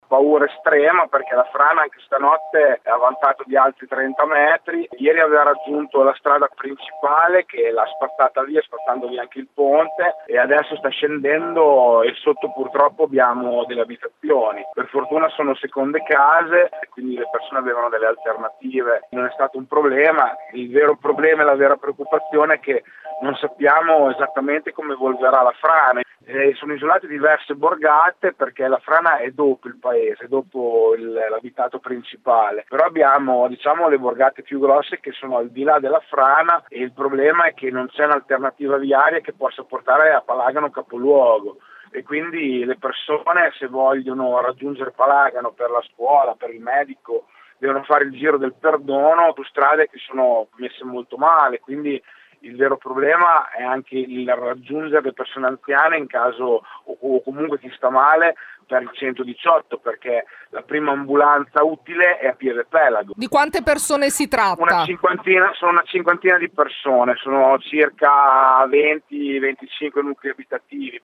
Sul posto il sindaco di Palagano e presidente della provincia di Modena Fabio Braglia: